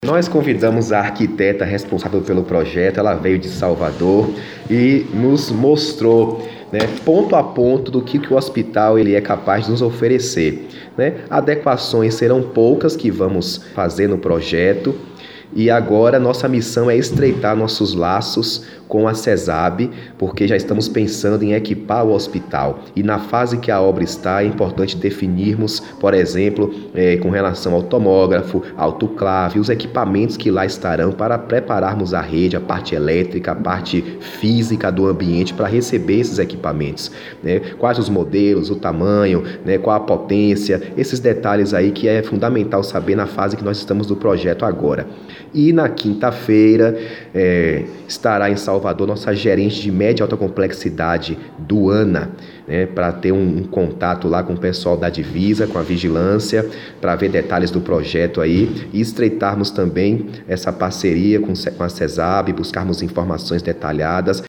Acesse o Podcast e ouça as explicações do Secretário de Saúde, Darkson Marques.